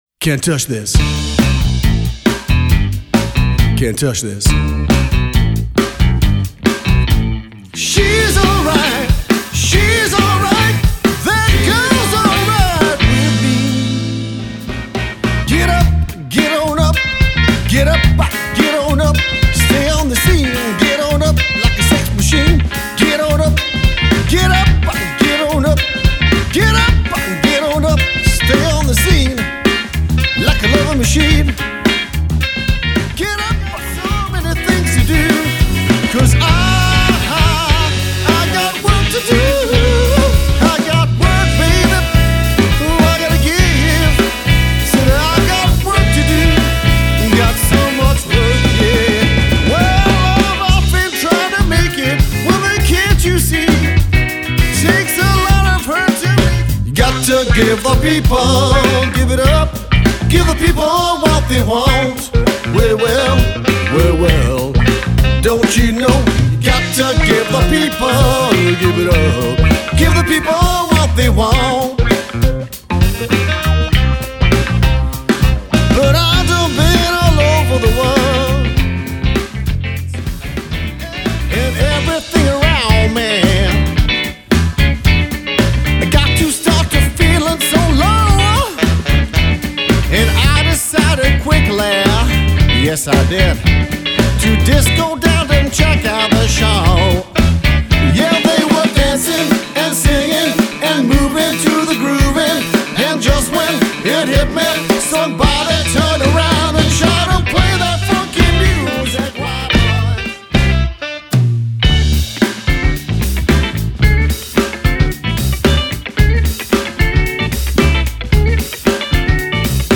Bass
Lead Vocal
Guitar
Drums
Saxophone